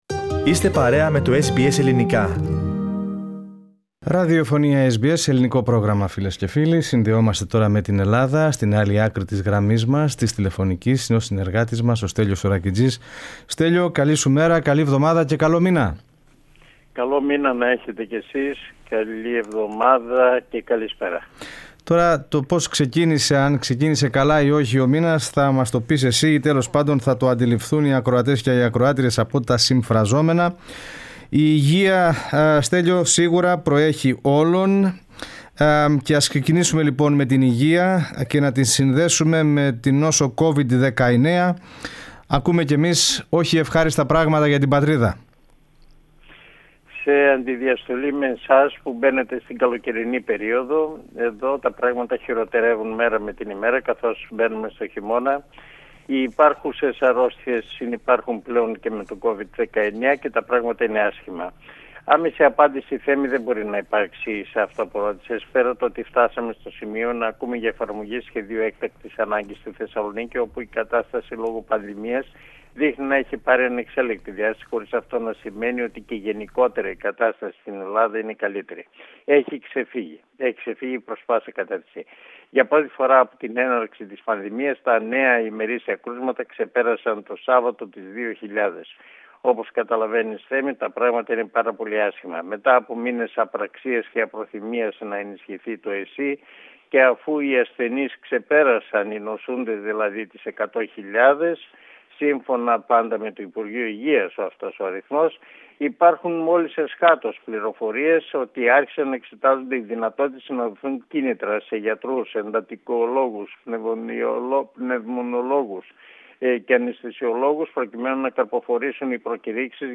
Η έξαρση της πανδημίας, ο σεισμός στη Σάμο και οι ελληνοτουρκικές σχέσεις στην εβδομαδιαία ανταπόκριση από την Αθήνα (2.11.2020).